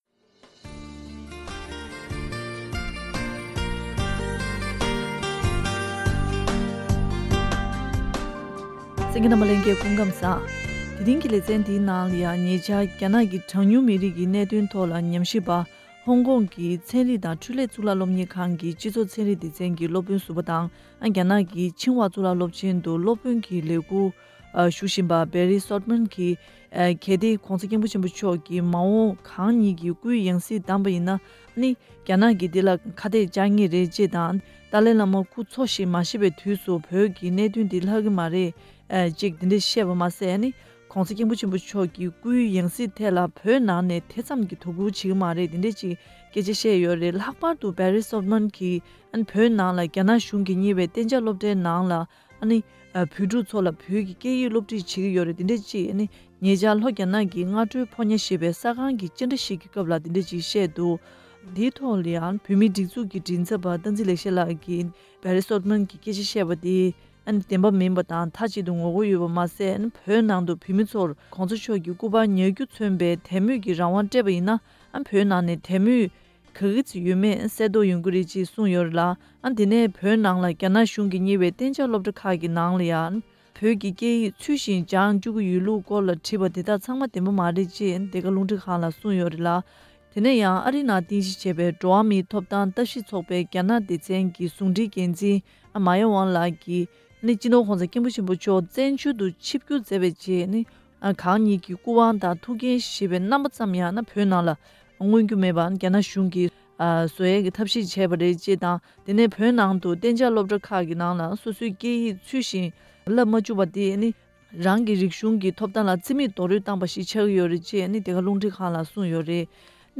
བཀའ་དྲི་ཞུས་པ་ཞིག